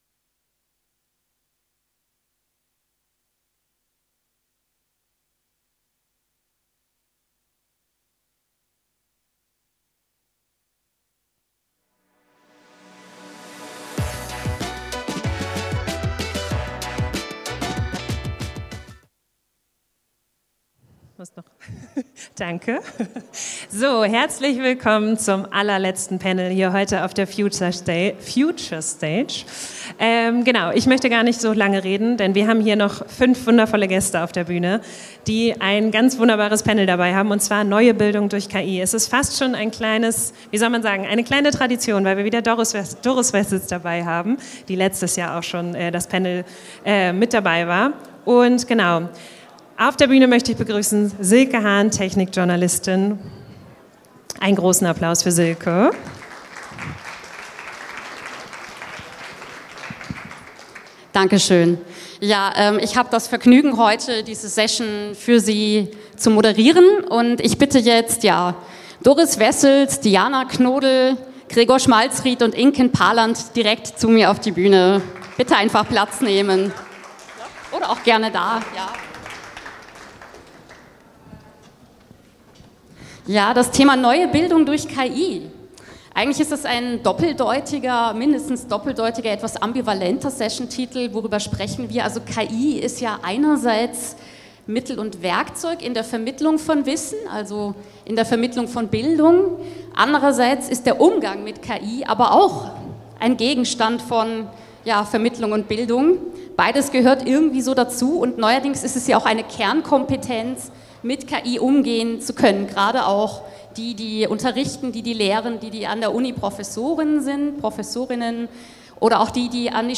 Doch was bedeutet das für Lehrkräfte, Schüler:innen und unser Bildungssystem insgesamt? In dieser Folge Signals from the Future diskutieren führende Expert:innen aus verschiedenen Bereichen darüber, wie KI bereits heute in der Bildung eingesetzt wird, welche Chancen sie bietet und welche Herausforderungen noch gemeistert werden müssen.